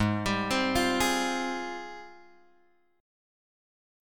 G# Major 13th
G#M13 chord {4 3 6 3 6 3} chord